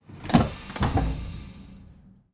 Train Door Compressed Air Sound
transport